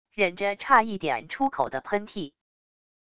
忍着差一点出口的喷嚏wav音频